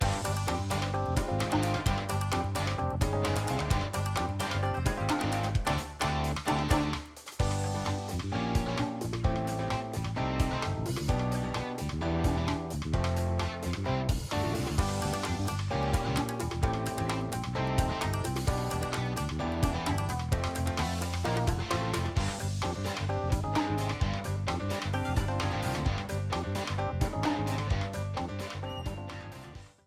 A battle theme
Ripped from the game
clipped to 30 seconds and applied fade-out